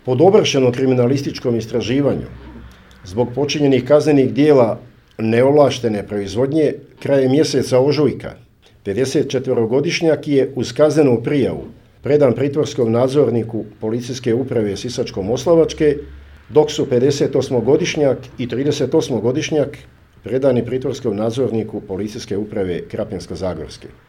O tome je danas na tiskovnoj konferenciji u Sisku izvjestio načelnik PU sisačko-moslavačke Luka Pešut